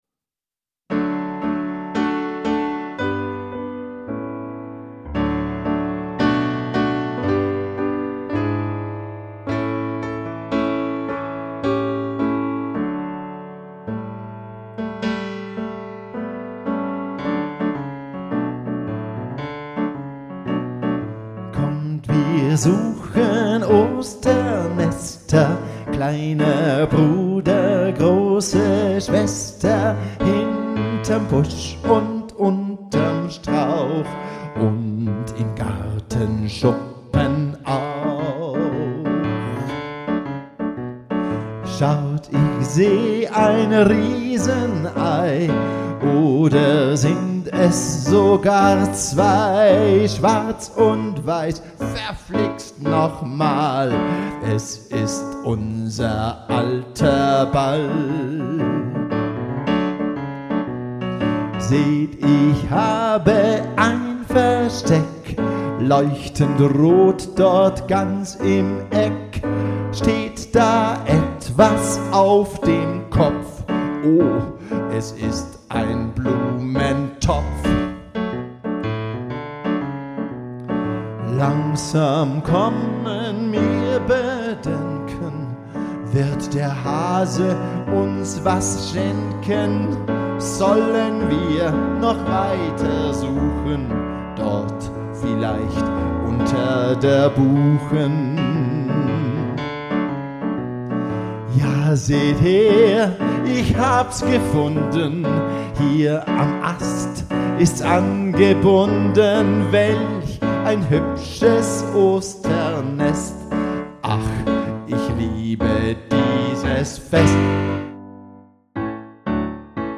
Klavier und Gesang